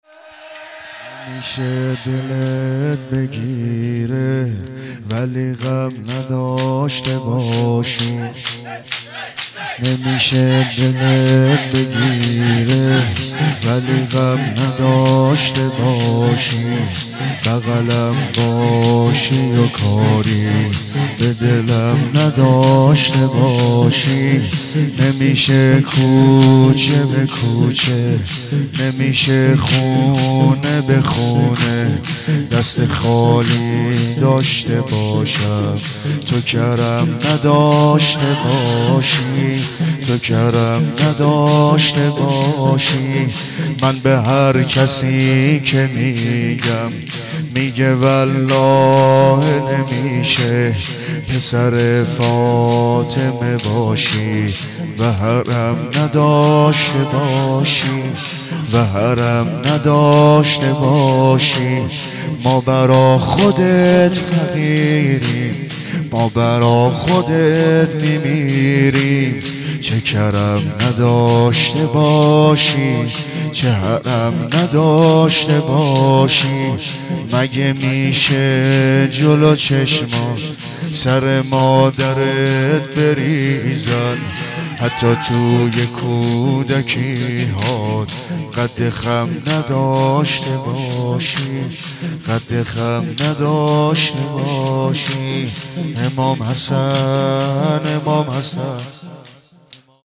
عقیق: مراسم عزاداری دهه دوم صفر در هیئت طفلان مسلم مرکز آموزش قرآن و معارف اسلامی برگزار شد.
نوحه پایانی